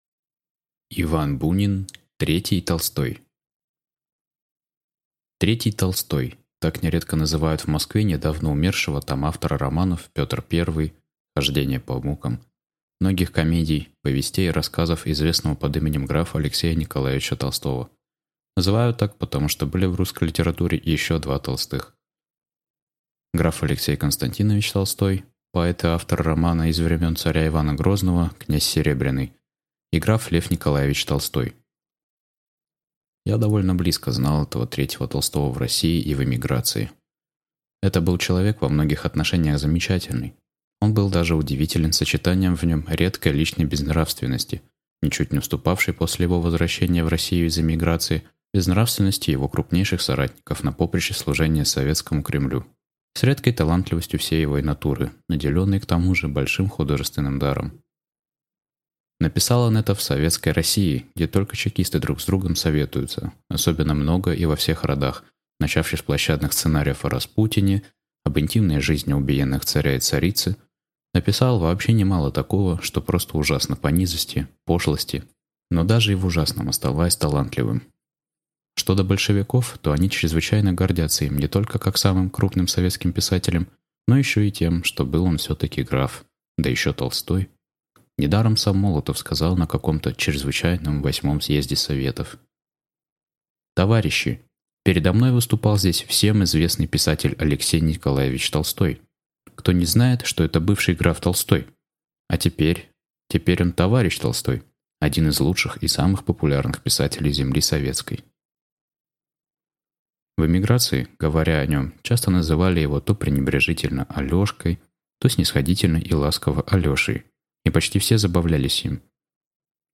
Аудиокнига Третий Толстой | Библиотека аудиокниг